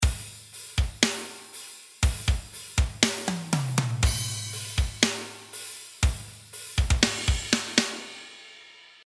drums.mp3